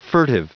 Prononciation du mot furtive en anglais (fichier audio)
Prononciation du mot : furtive